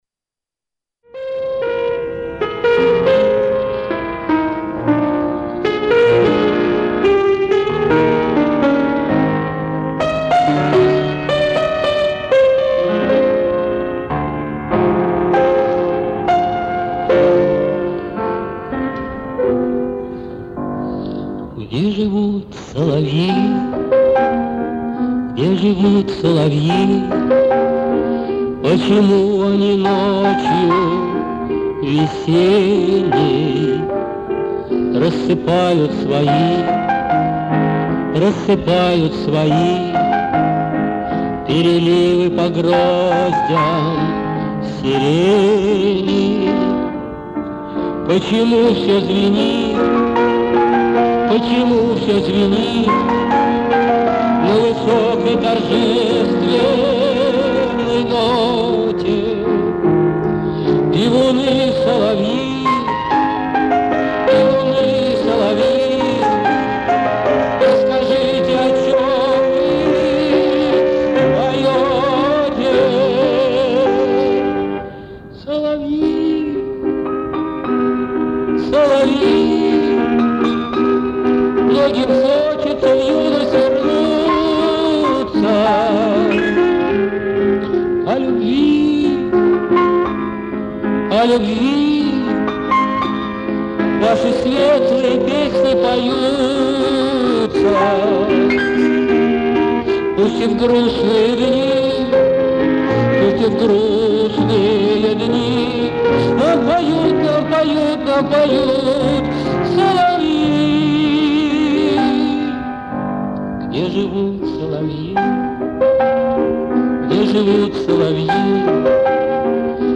вокал, гитара
Исп. автор.